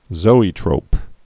(zōē-trōp)